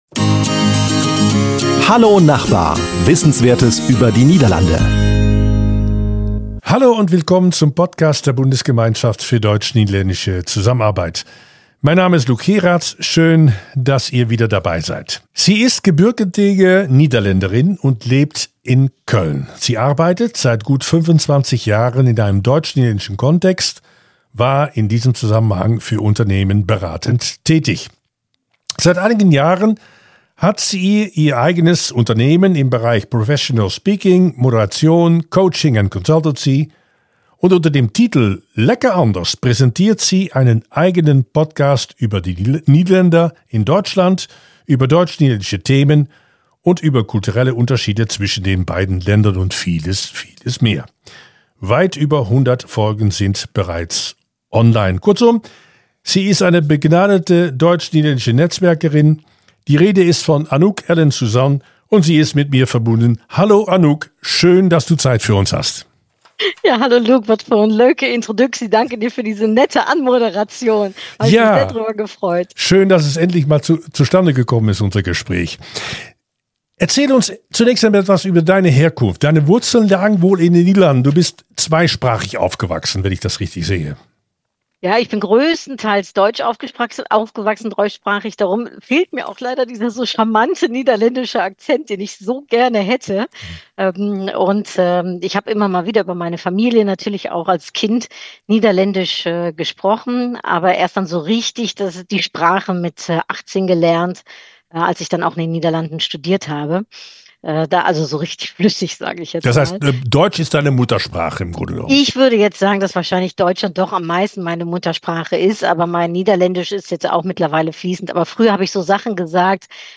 #42 Eine DNL-Netzwerkerin und Podcasterin im Gespräch ~ Hallo Nachbar! Wissenswertes über die Niederlande Podcast